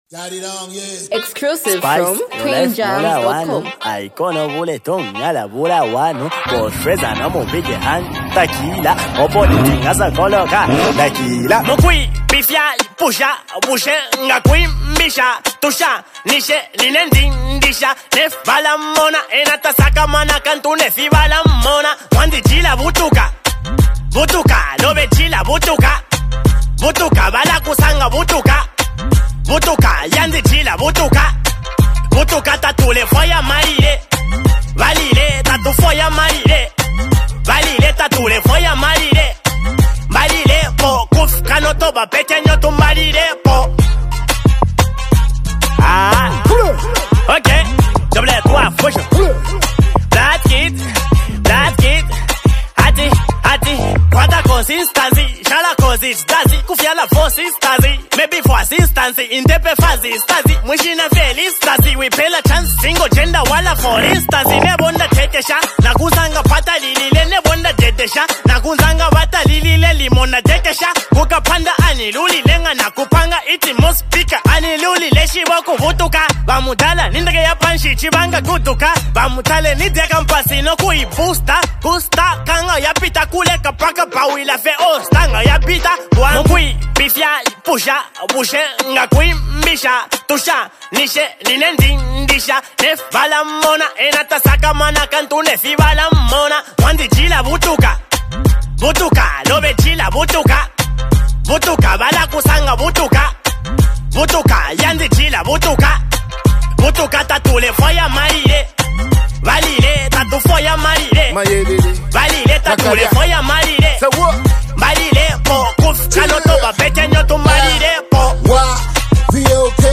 motivational hip-hop anthem